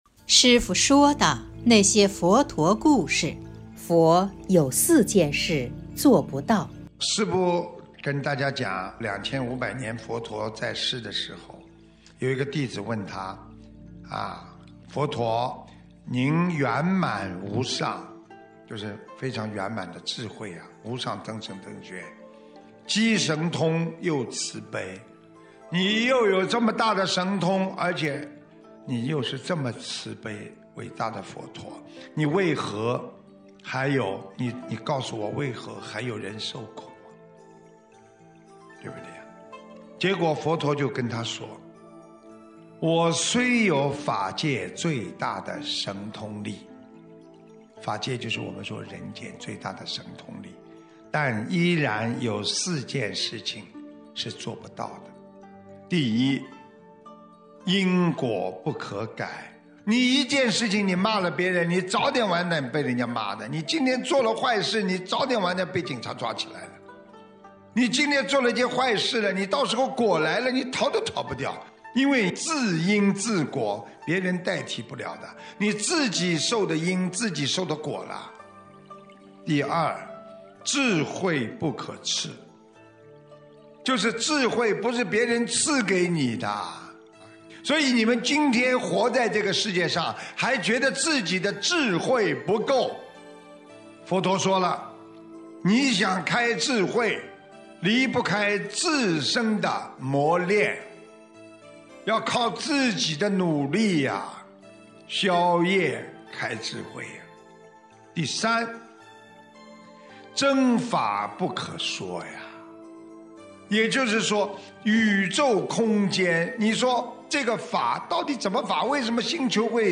音频：《佛有四件事做不到的事》师父说的那些佛陀故事！（后附师父解说）！2020年03月04日【师父原声音】